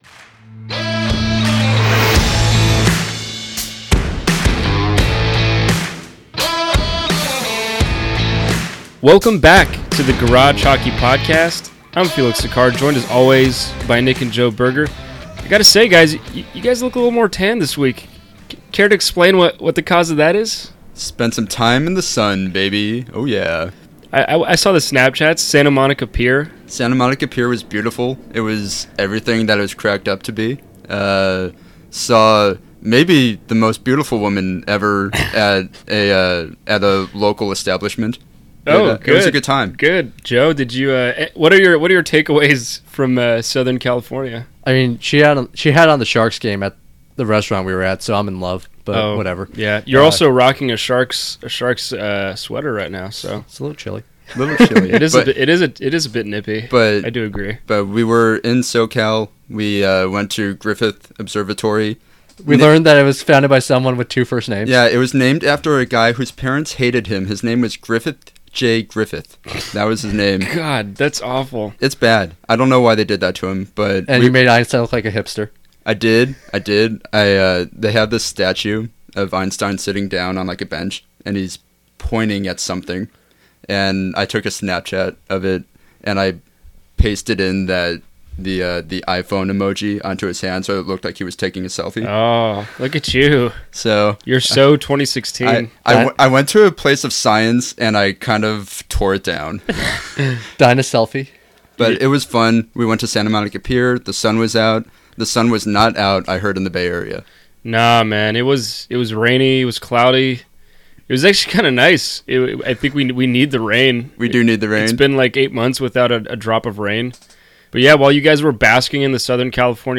in the studio to talk some hockey